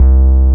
46BASS01  -L.wav